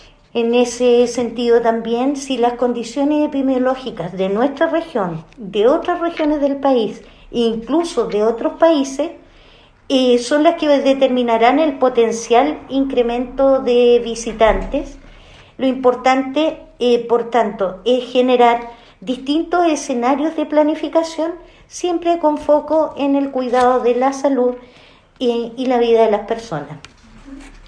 La seremi de Salud, Regina Barra, dijo que es muy pronto para indicar cómo serán las condiciones epidemiológicas en esa fecha, por lo que consideró que no deben hacer proyecciones a largo plazo.